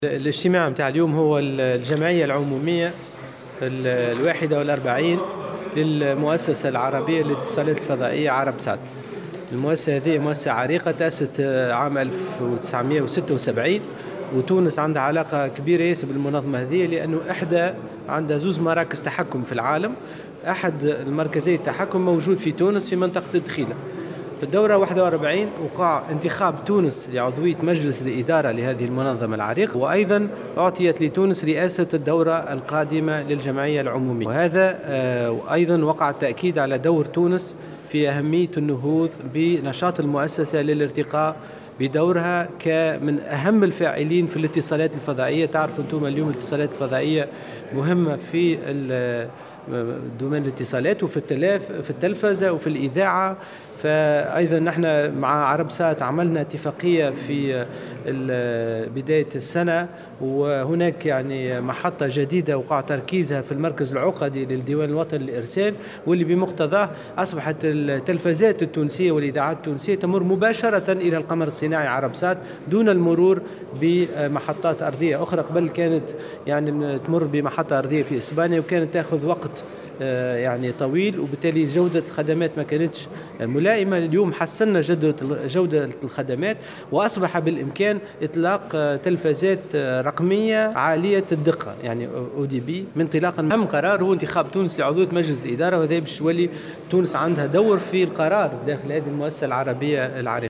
أكد وزير تكنولوجيات الاتصال والاقتصاد الرقمي، أنور معروف في تصريح لمراسلة الجوهرة "اف ام" اليوم الخميس انتخاب تونس لعضوية المؤسسة العربية للاتصالات الفضائية "عربسات".